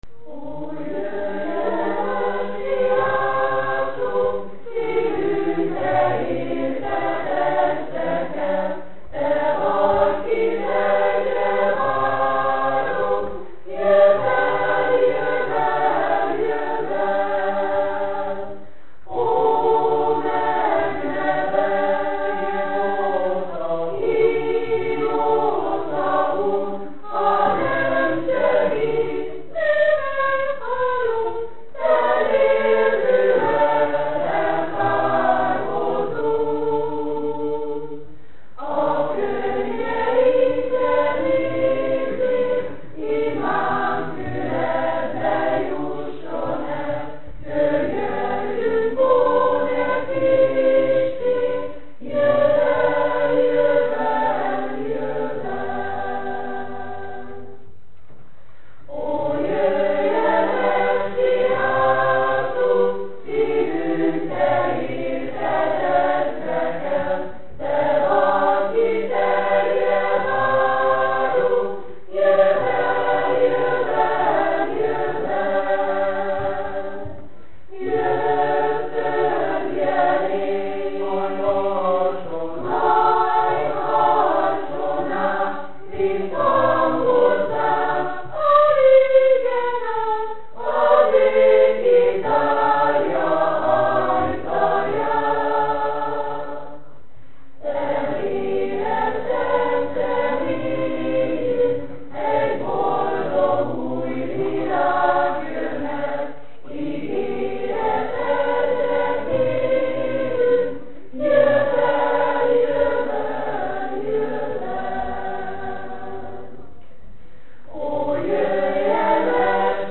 Énekkar